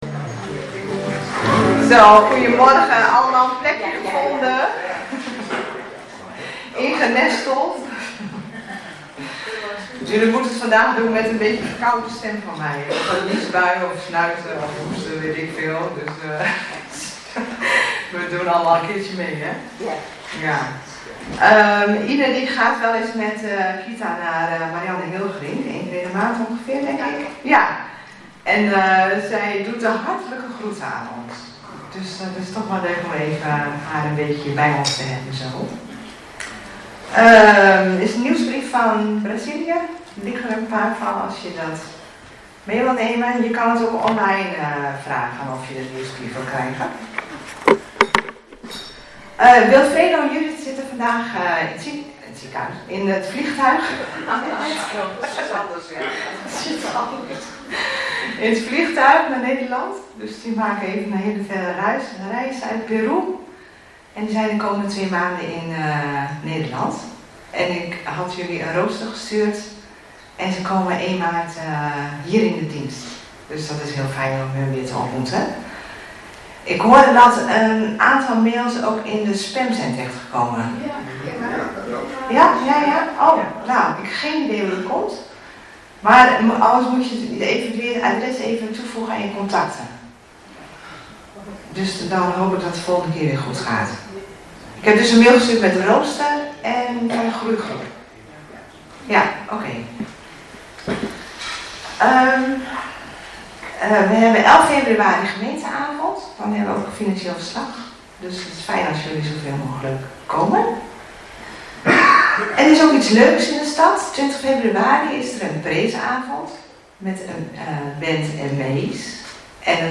1 februari 2026 dienst - Volle Evangelie Gemeente Enschede